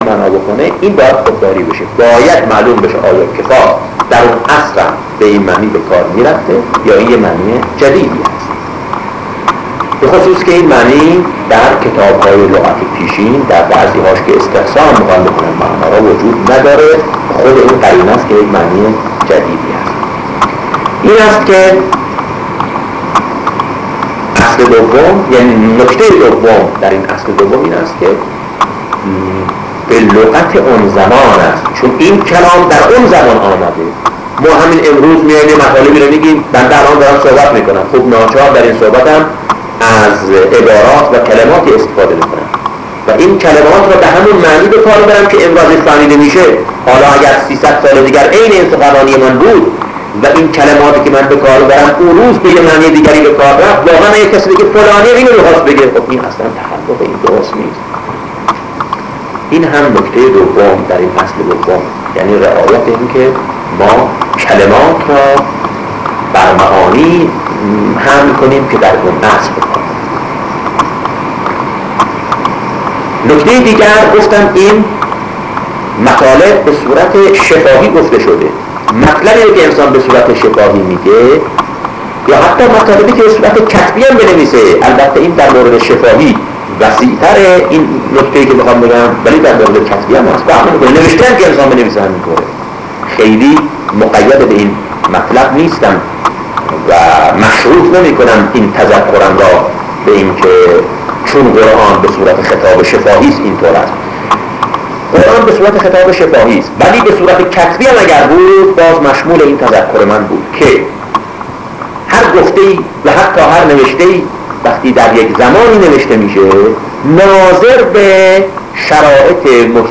فایل صوتی کتاب ‌روش‌ برداشت‌ از قرآن‌ که حاصل مصاحبۀ شهيد حجت‌الاسلام‌ علي‌اكبراژه‌ای با شهید دکتر بهشتی است منتشر شد.
این‌ مصاحبه‌ در فروردين‌ماه‌ 1356( ش)‌ توسط شهيد حجت‌الاسلام‌ علي‌اكبراژه‌اي در اصفهان با شهيد آيت‌الله‌ دكتر بهشتي تحت‌ عنوان‌روش‌ برداشت‌ از قرآن‌ به‌ عمل‌ آمده‌ است‌ که درآغاز به‌ صورت‌ نوار در اختيار علاقه‌مندان‌ قرار گرفت‌ و پس‌از پيروزي‌ انقلاب‌ اسلامي‌، روزنامه‌ جمهوري‌ اسلامي‌ اقدام‌ به‌چاپ‌ آن‌ كرد.